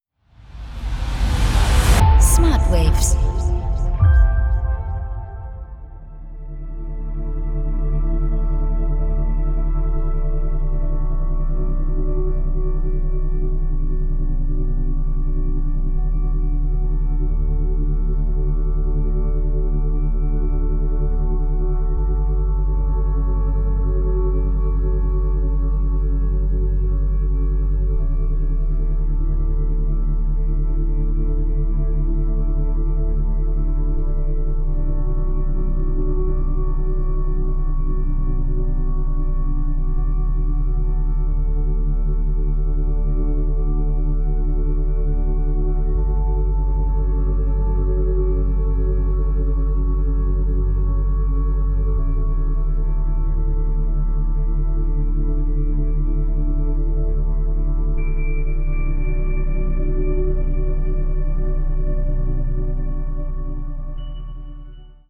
entspannende und schwebende Umgebungsgeräusche
• Methode: Binaurale Beats